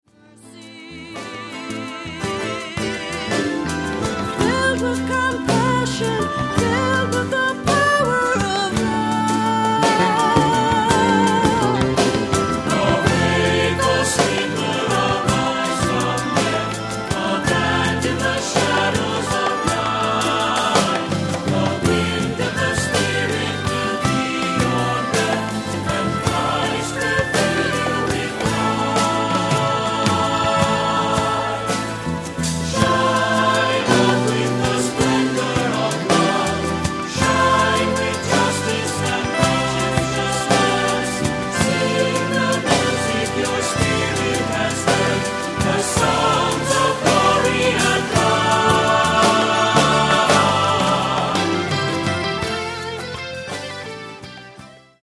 Accompaniment:      Keyboard, C Instrument I;C Instrument II
Music Category:      Christian